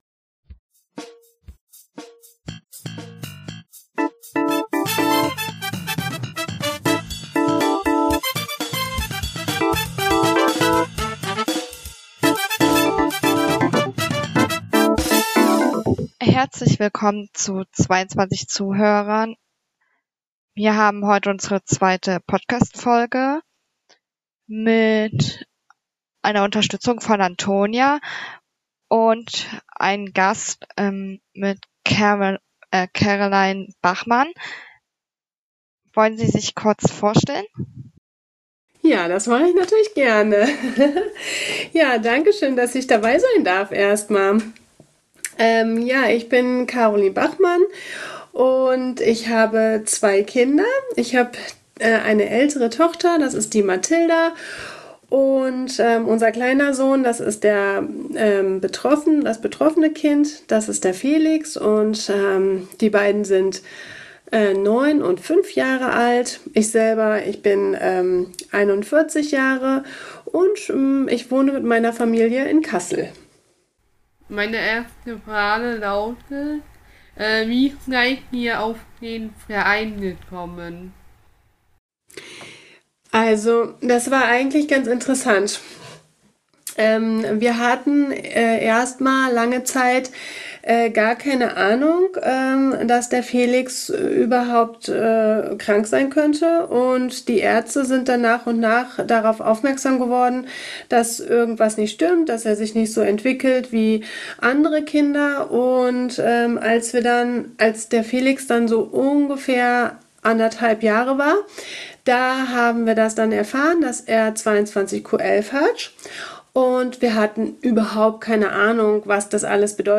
Darüber, wie es ist, ein betroffenes Kind zu bekommen und zu haben, besprechen wir mit zwei Müttern, die im Verein aktiv sind.